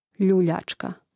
ljùljāčka ljuljačka